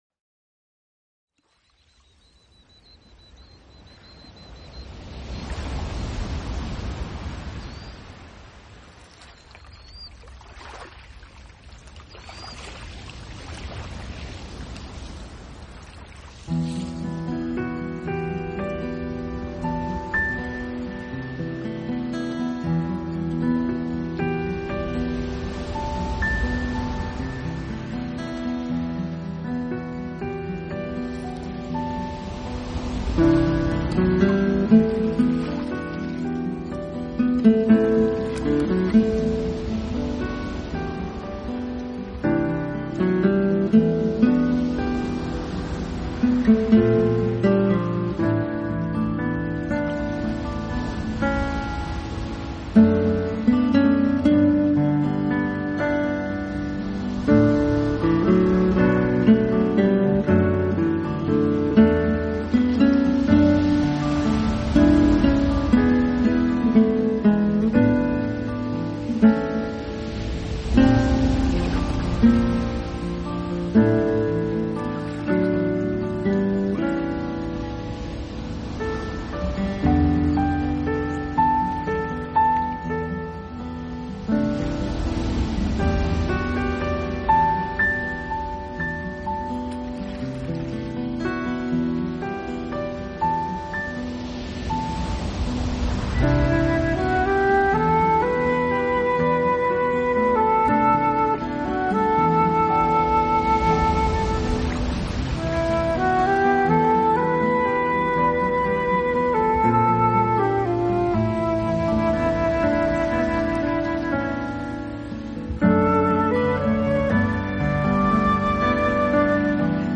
【纯音乐】
讓身心放假… 迎著自然海風 乘著舒緩樂音享受悠閒的獨處時光 體驗自在的生活閒情…
的氛圍，感受屬於自己的獨處時光，不是寂寞，而是一種讓身心靈完全放鬆的舒服感覺。